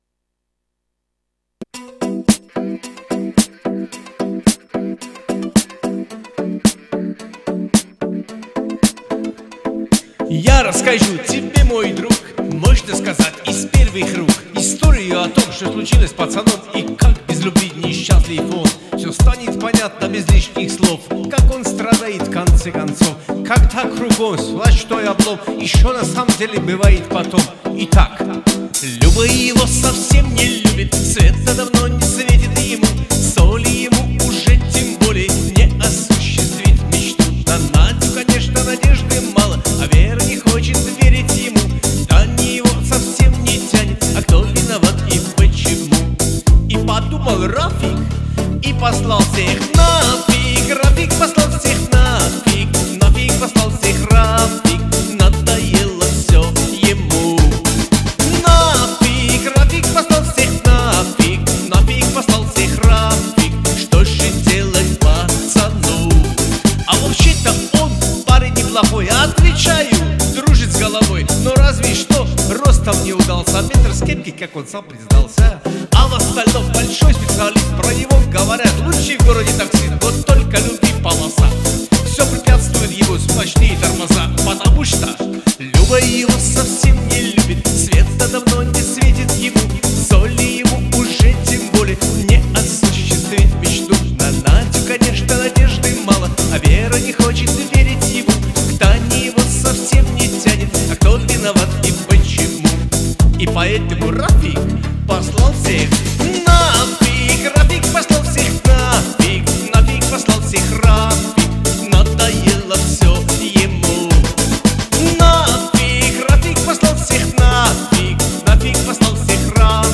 шуточная, весёлая